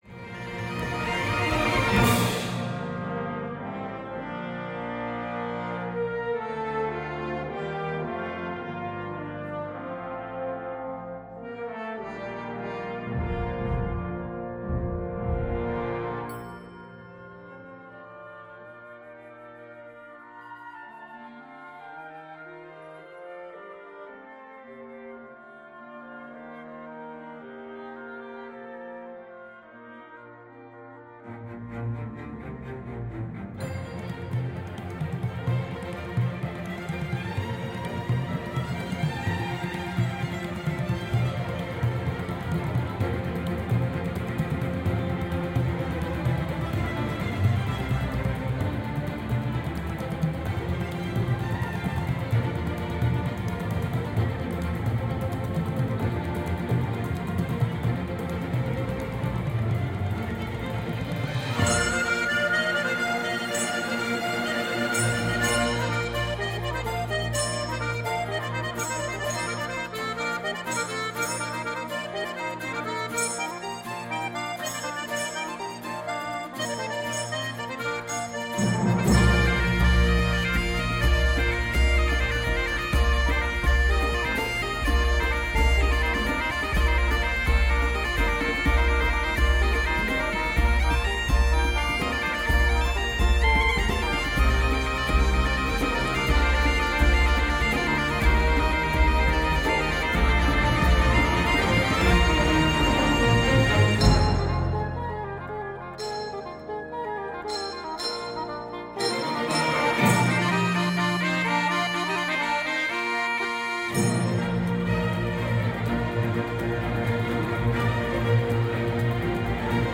cantata para Celtas y orquesta